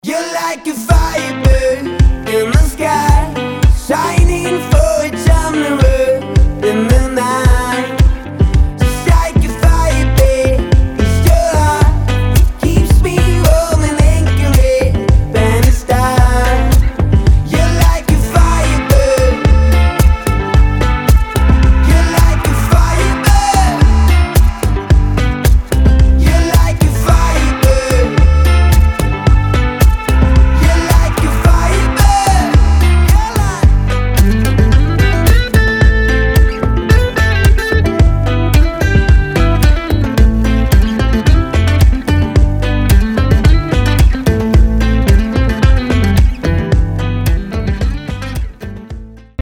• Качество: 320, Stereo
гитара
мужской вокал
deep house
dance
спокойные
tropical house
indie pop